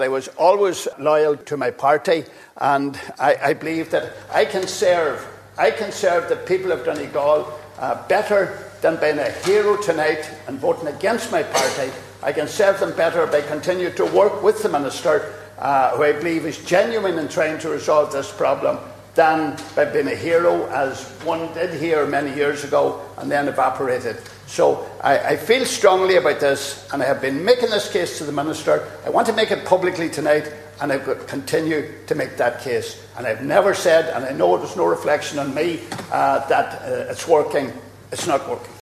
During the debate, Donegal TD Pat the Cope Gallagher said he could vote against his party, Fianna Fail, but he believes he can serve the people of Donegal better by working with the government to secure more improvements.